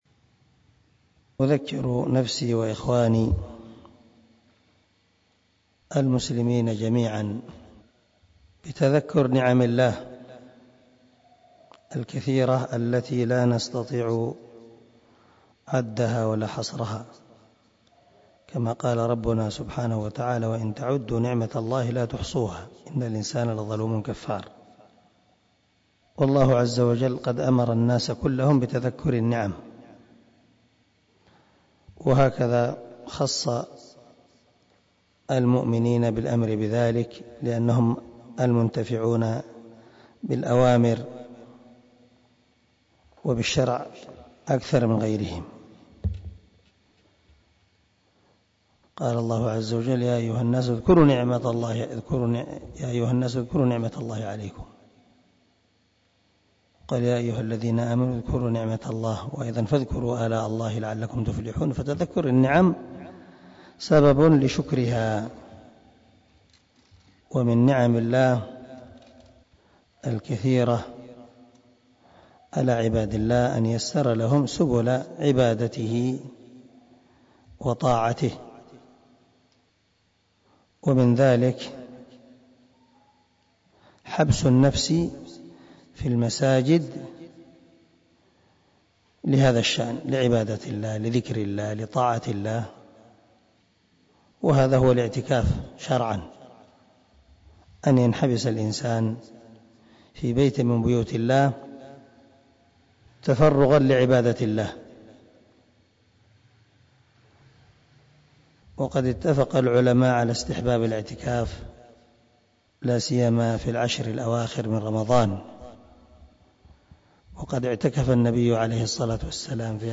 كلمة عظيمة وموعظة مؤثرة